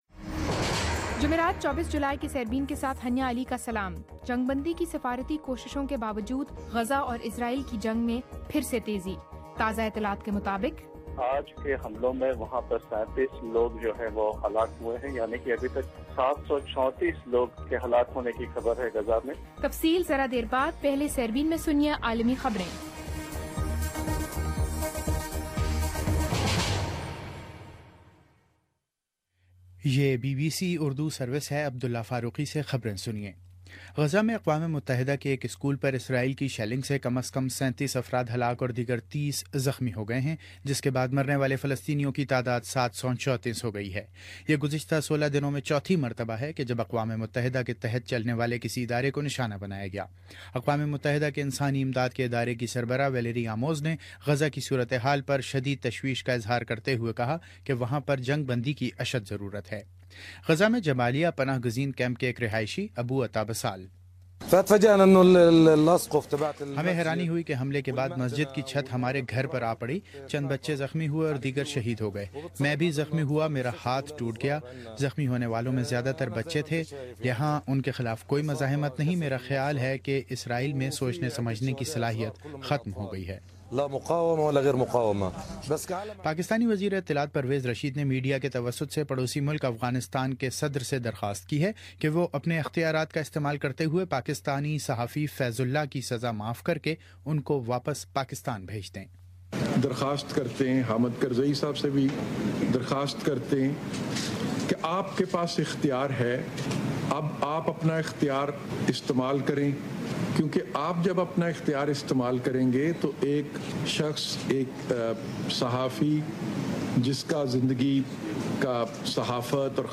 جمعرات 24 جولائى کا سیربین ریڈیو پروگرام